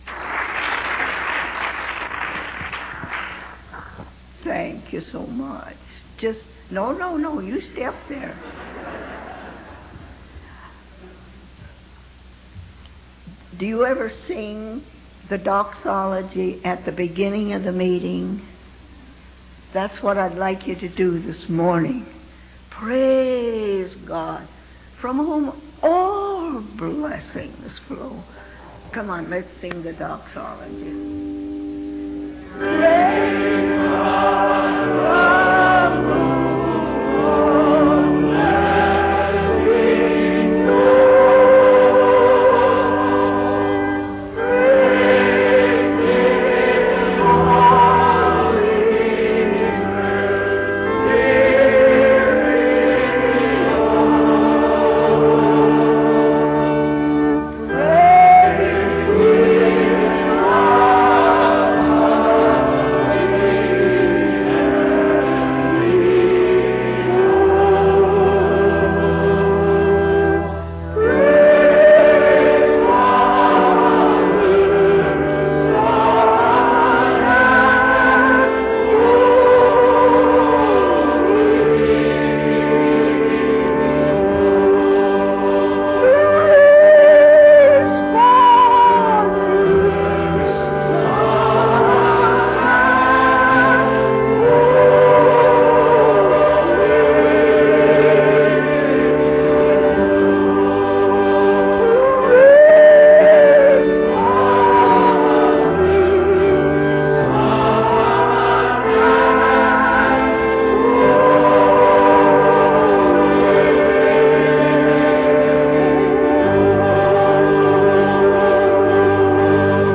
In this sermon, the preacher emphasizes the potential and growth that lies within each individual. He compares the development of a person from a tiny seed to the nine-month process of pregnancy, highlighting the importance of nurturing and preparation for a new life. The preacher also discusses the power of Jesus in overcoming challenges and encourages pastors to address the realities and problems within their congregations.